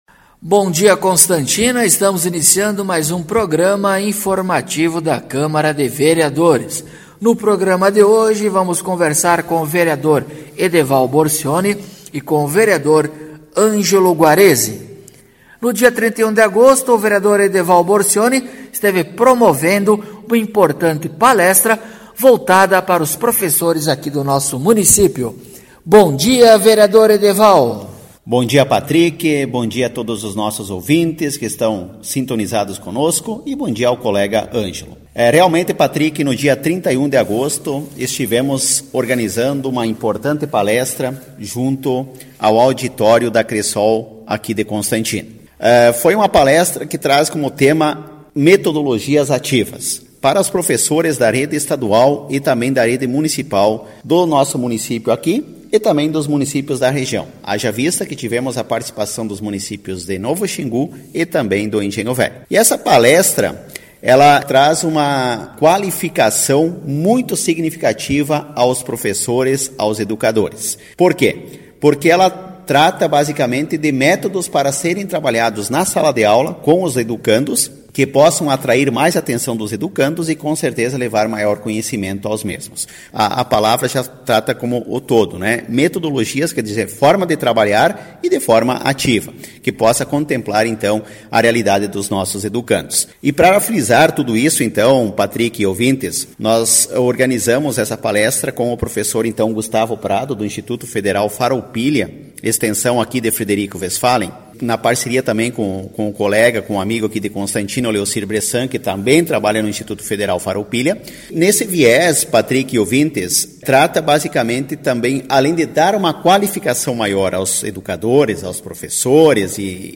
Acompanhe o programa informativo da câmara de vereadores de Constantina com o Vereador Edeval Borcioni e o Vereador Angelo Guarezi.